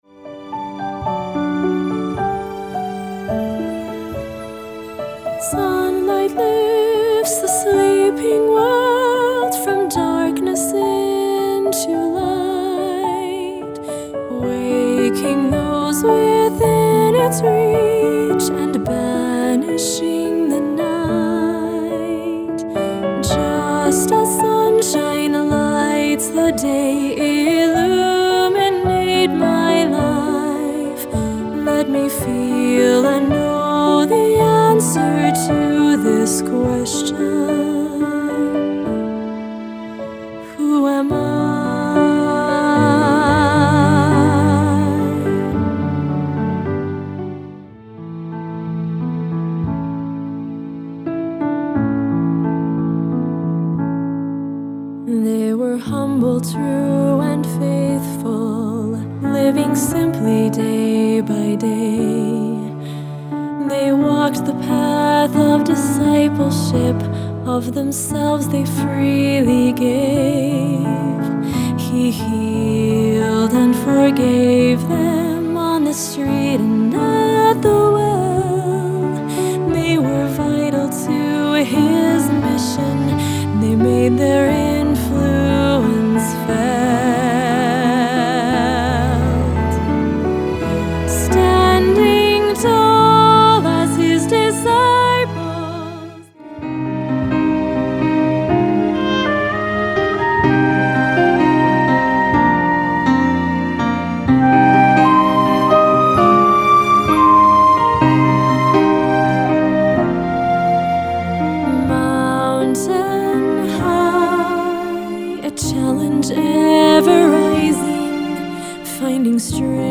AND Bonus Instrumental/Performance Tracks for the following: